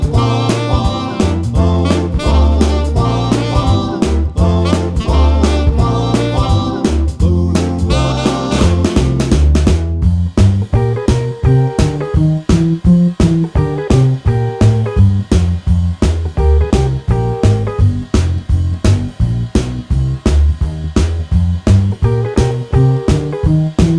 Minus Sax Solo Rock 'n' Roll 2:21 Buy £1.50